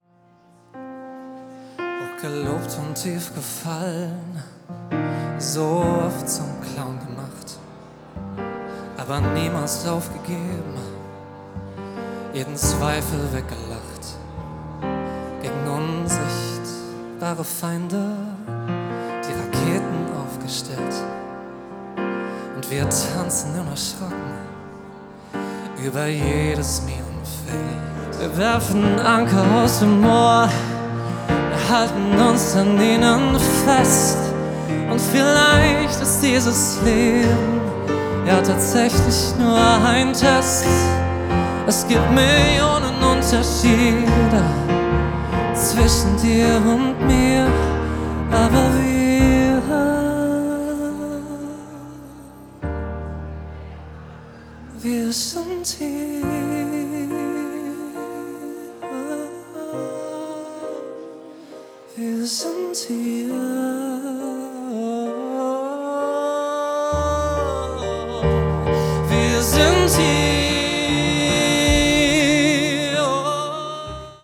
live von der celebrations Hochzeitsmesse Frankfurt